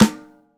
• Reverb Steel Snare Drum A Key 76.wav
Royality free acoustic snare sound tuned to the A note.
reverb-steel-snare-drum-a-key-76-5CY.wav